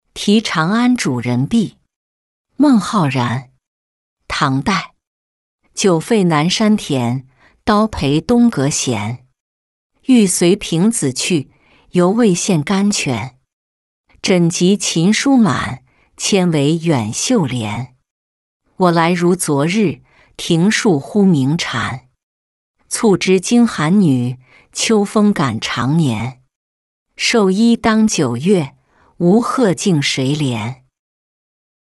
题长安主人壁-音频朗读